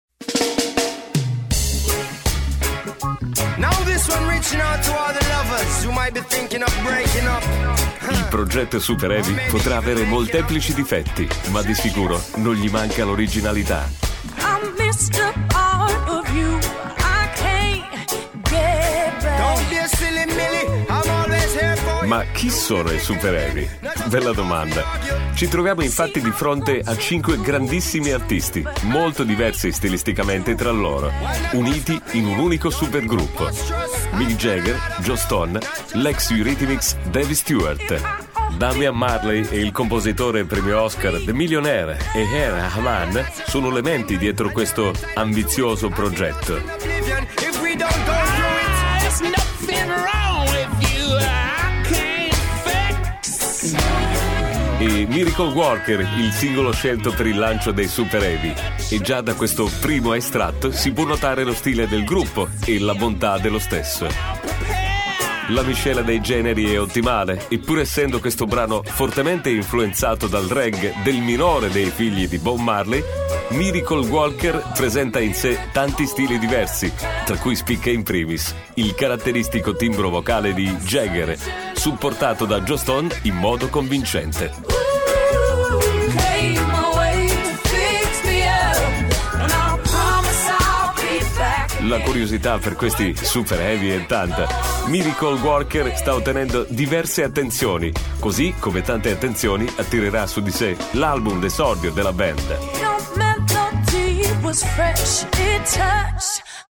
Voce classica radiofonica.
Sprechprobe: eLearning (Muttersprache):